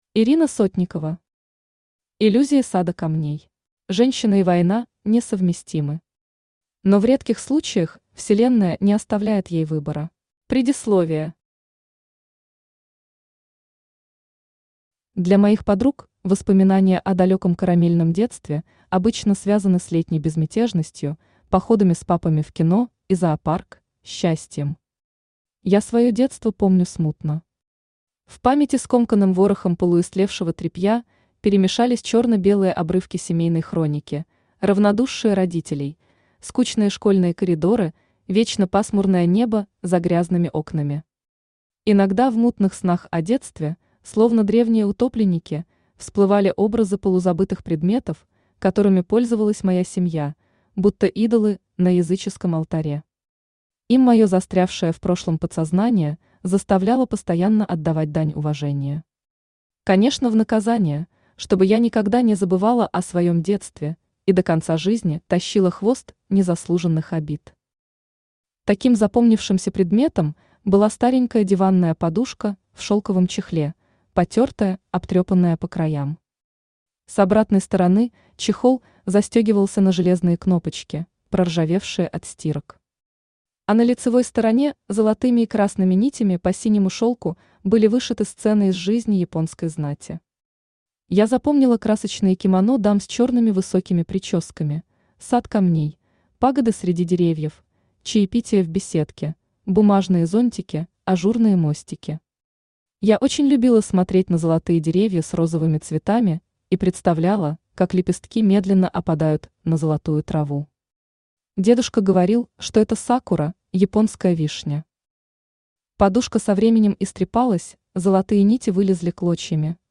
Аудиокнига Иллюзии сада камней | Библиотека аудиокниг
Aудиокнига Иллюзии сада камней Автор Ирина Сотникова Читает аудиокнигу Авточтец ЛитРес.